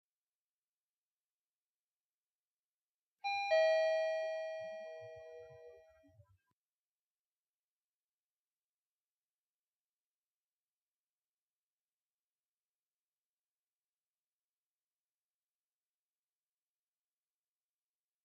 Elevator Ding Sound Button - Botón de Efecto Sonoro